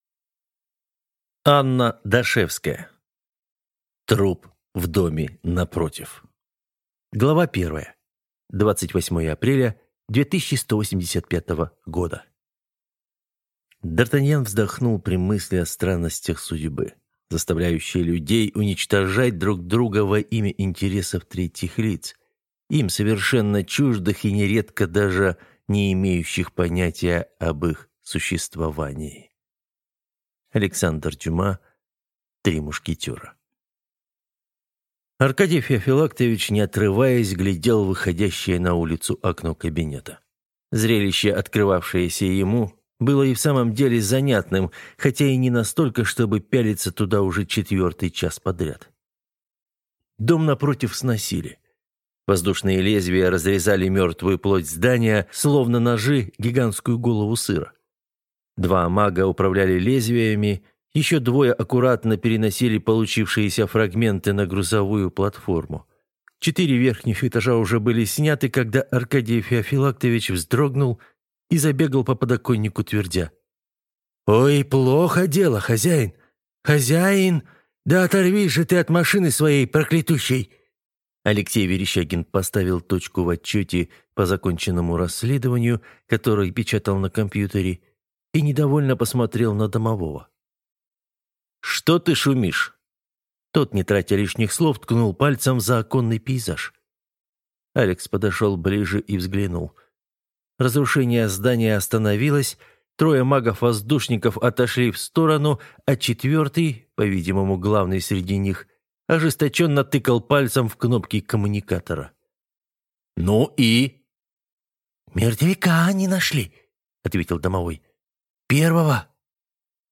Аудиокнига Труп в доме напротив | Библиотека аудиокниг